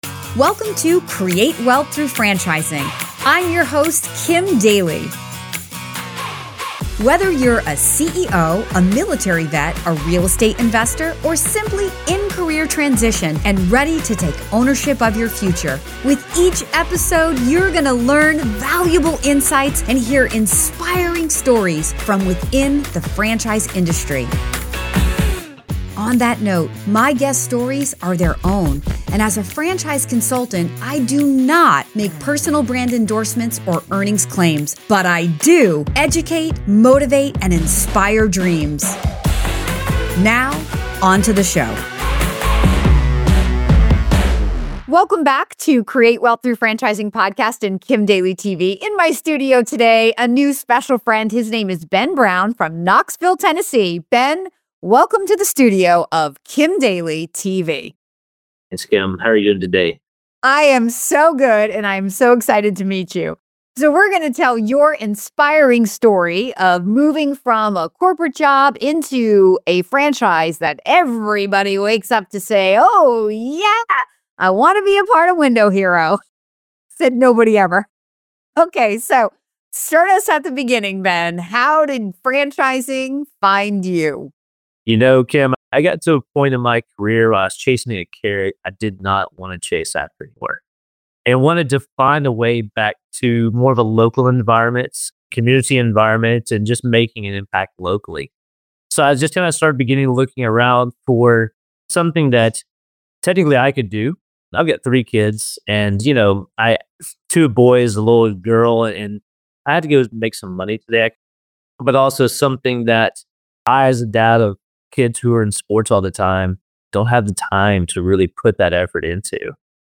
This episode offers a lively discussion on creating wealth through franchising, focusing on community-oriented service businesses.